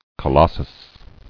[co·los·sus]